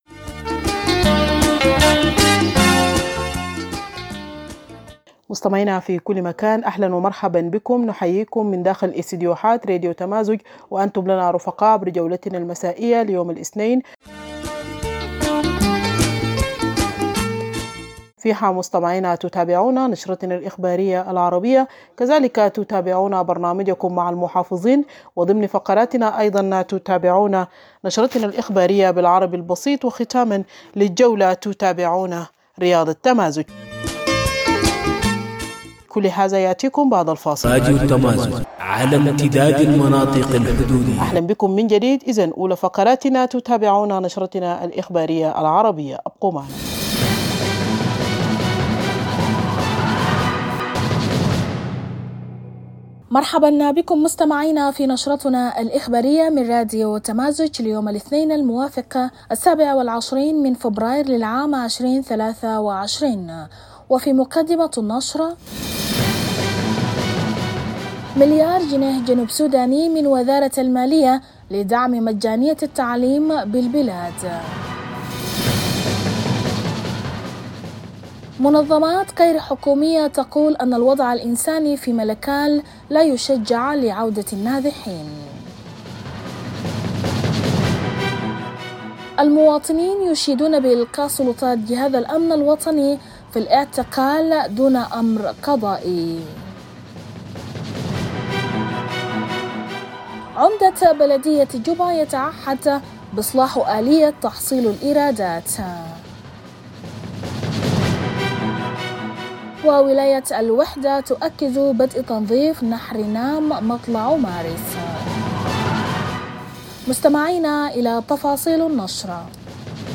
Evening Broadcast 27 February - Radio Tamazuj
INTERVIEW: ‘Machar to visit states after deployment of unified forces’-SPLM-IO